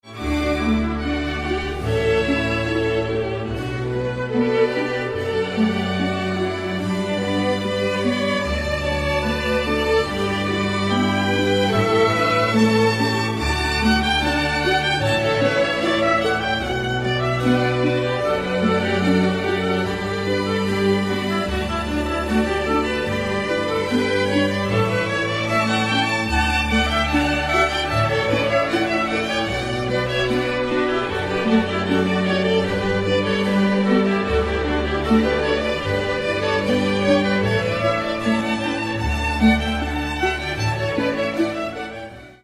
D   A   Bm   F#m   G   D   G   A
آثاری که می‌شنوید همگی بر اساس توالی آکوردی پاخلبل ساخته شده است:
audio fileپاخلبل: کنُن در ر ماژور برای ارکستر زهی و هارپسیکورد